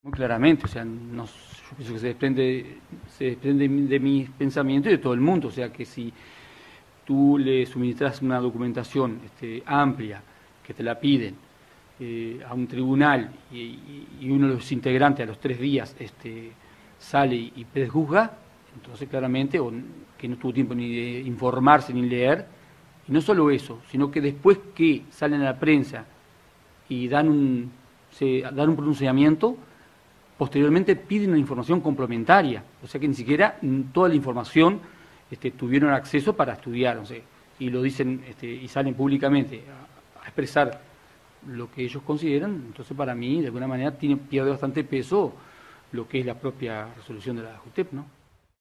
Entrevistado por el portal Agesor, Bascou dijo que leyó el informe con sus asesores, y aseguró que el contenido "era previsible";.